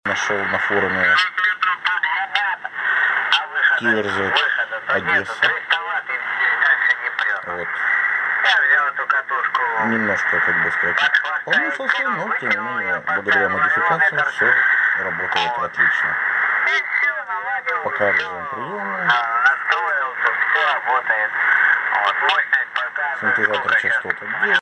( на качество звука я внимание не обращаю, хотя это полный ххх ... )
АРУ слабовато, переход с передачи на прием задерживается, пока все.